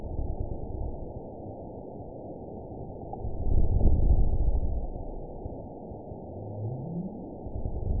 event 920230 date 03/07/24 time 20:39:26 GMT (1 year, 3 months ago) score 9.40 location TSS-AB05 detected by nrw target species NRW annotations +NRW Spectrogram: Frequency (kHz) vs. Time (s) audio not available .wav